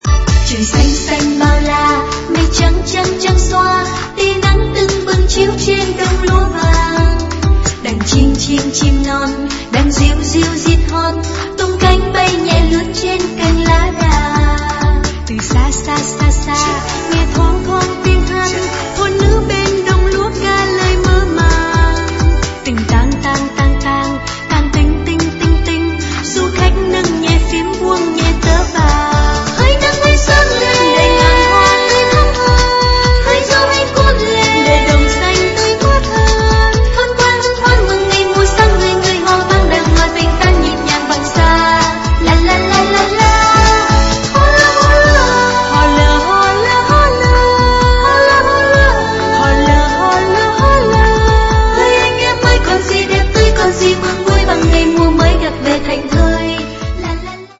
Hòa tấu